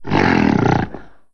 c_goril_atk2.wav